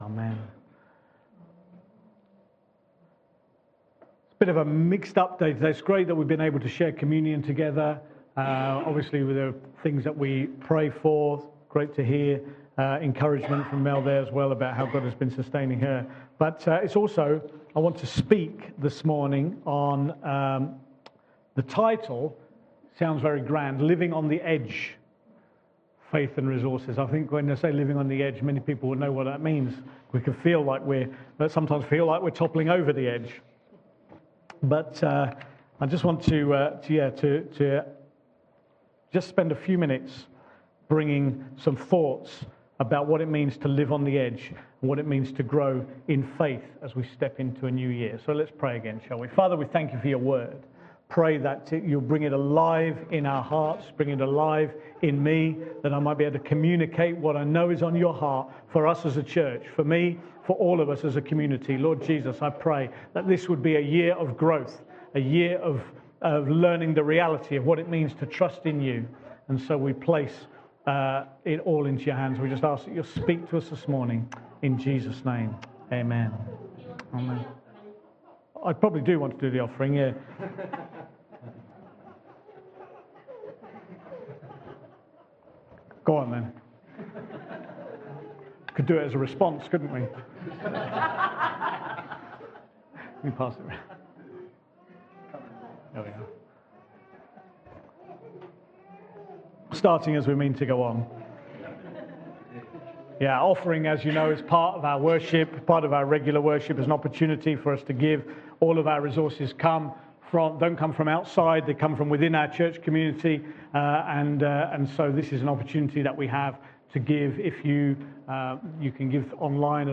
One Off Sunday Gathering – Living on the edge – Faith and resources